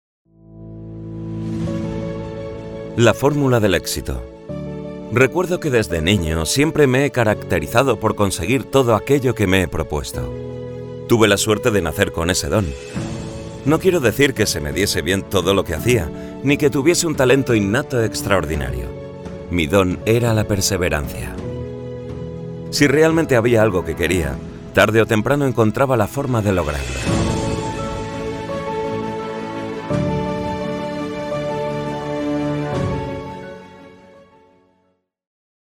Male
Madrid nativo
Microphone: Neumann Tlm 103